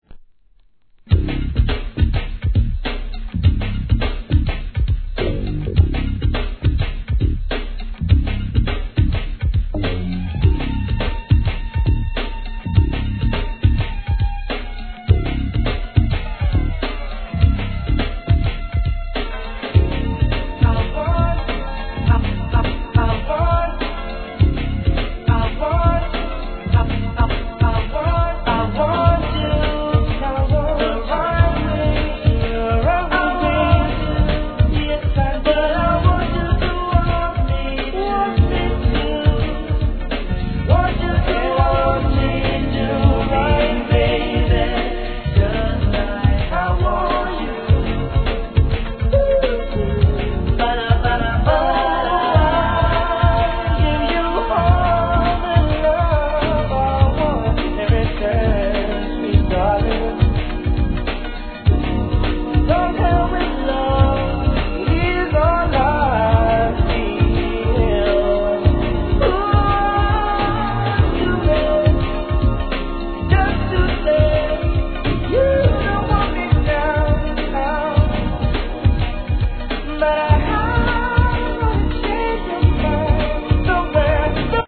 HIP HOP/R&B
SLOWでソウル・フルに聴かせるNICEナンバー!